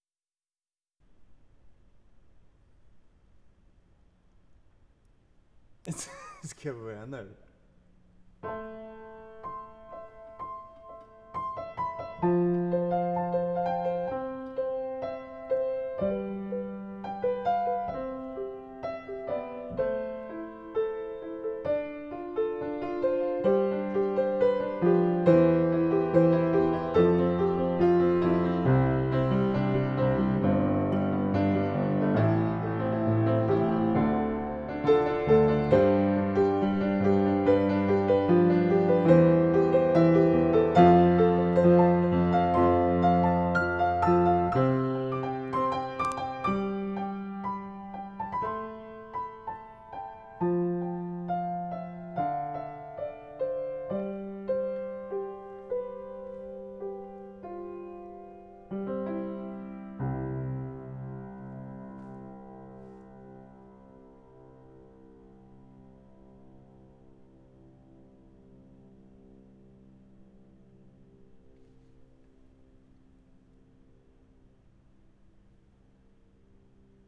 En liten pianotruddilutt! Quick and dirty, ingen "performance".
Inspelat med Line Audio CM3 samt 8MP i ORTF.
Har inte hunnit lyssna ut i stereon men i lurar låter det rätt trevligt trots brusig lokal.
Piano-co.wav